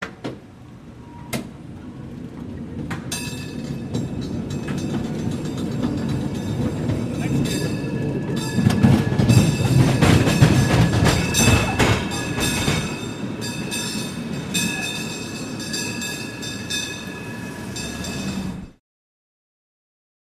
Cable Car, San Francisco, Start and Away With Bell Ringing